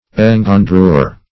Search Result for " engendrure" : The Collaborative International Dictionary of English v.0.48: Engendrure \En`gen*drure"\, n. [OF. engendreure.] The act of generation.